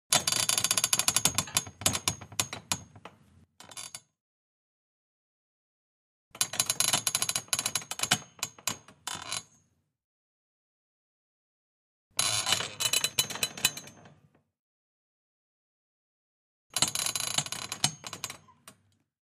Springs, Camp Bed, Creak x4